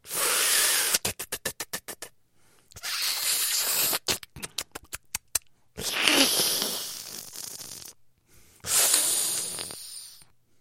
Высасывает молочко словно вампир